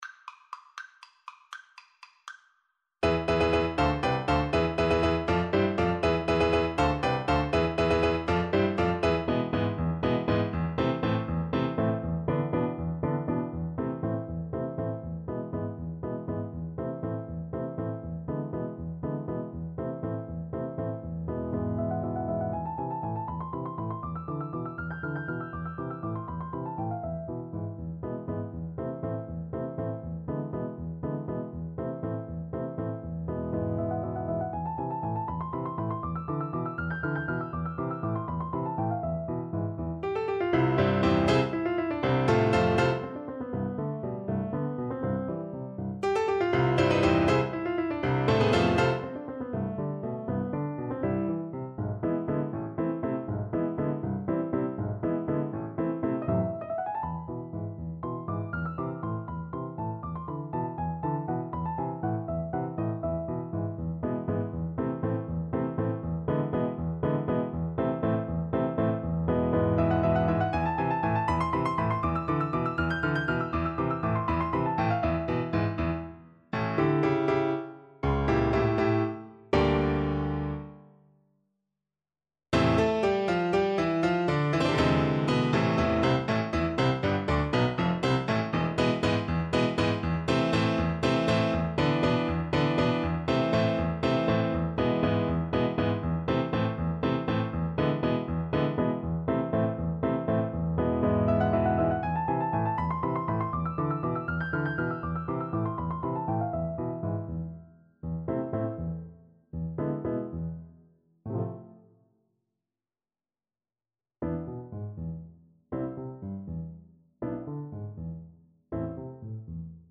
Play (or use space bar on your keyboard) Pause Music Playalong - Piano Accompaniment Playalong Band Accompaniment not yet available transpose reset tempo print settings full screen
3/8 (View more 3/8 Music)
A minor (Sounding Pitch) (View more A minor Music for Cello )
Allegro vivo (.=80) (View more music marked Allegro)